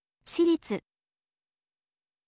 shiritsu